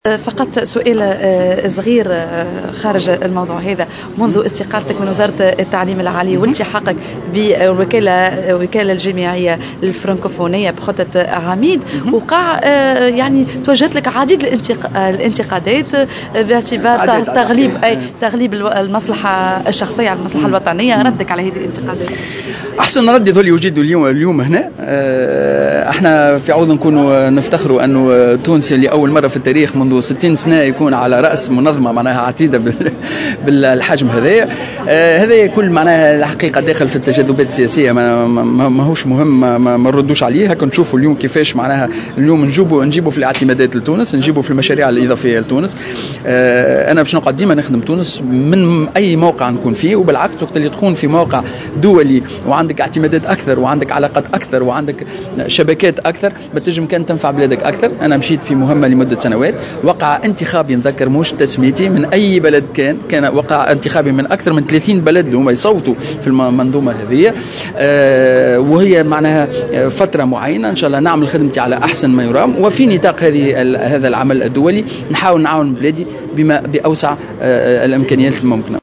وأوضح في تصريح لموفدة "الجوهرة اف أم"